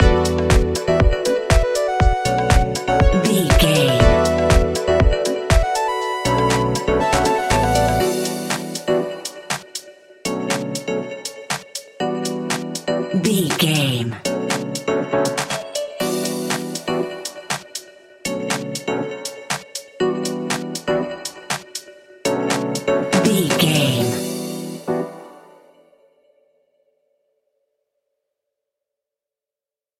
Dorian
groovy
uplifting
driving
energetic
drum machine
synthesiser
funky house
upbeat
funky guitar
clavinet
synth bass